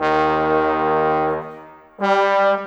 Rock-Pop 07 Trombones _ Tuba 05.wav